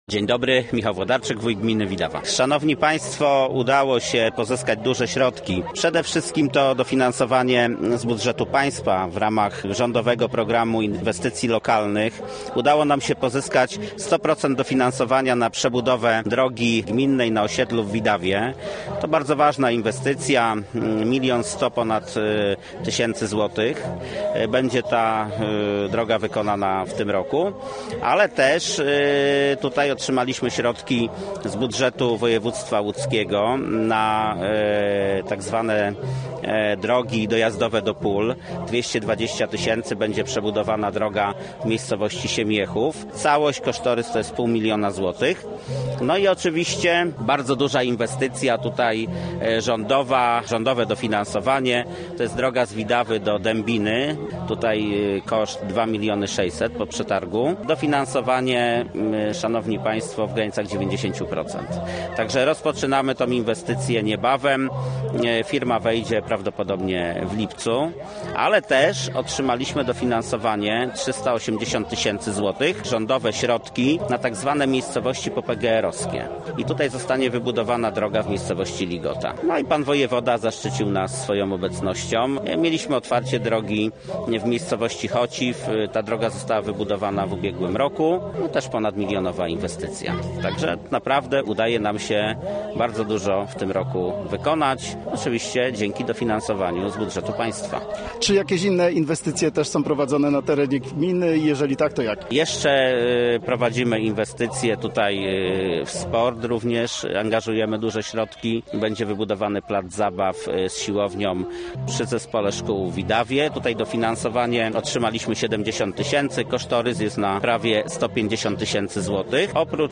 Gościem Radia ZW był Michał Włodarczyk, wójt gminy Widawa